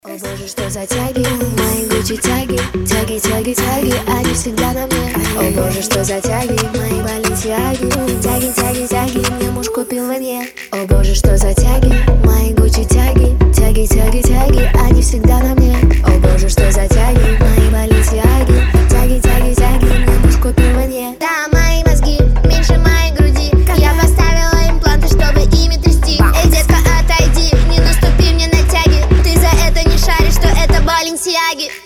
jersey club